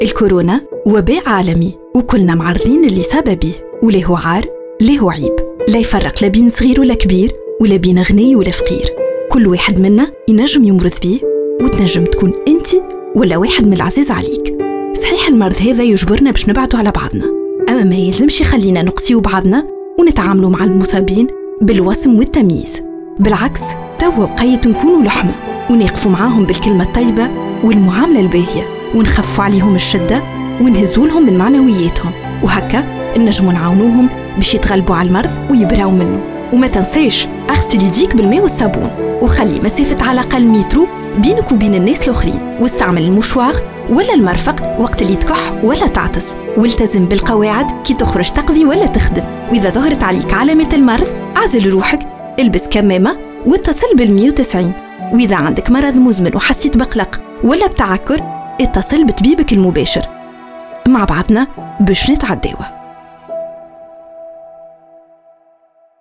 spot radio stigma covid de confinement
spot-radio-stigma-covid-de-confin-1.wav